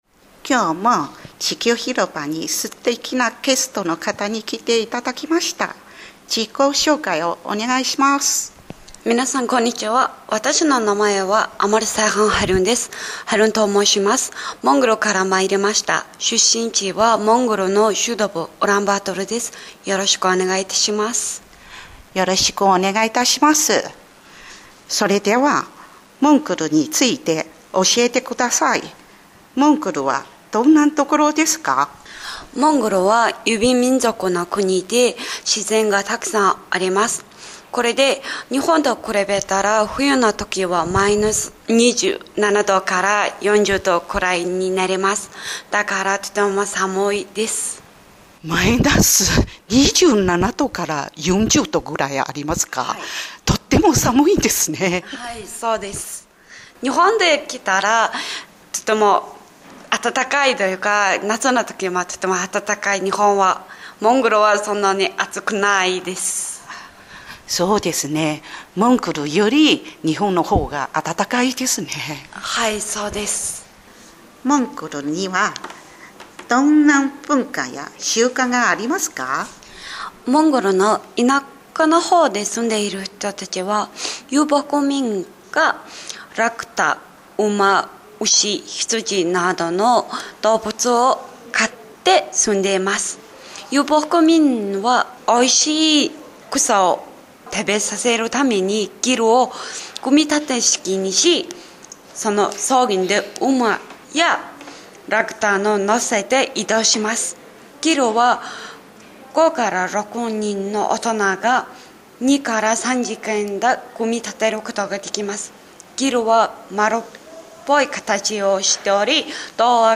2024年4月～6月インタビュー
interview2404.mp3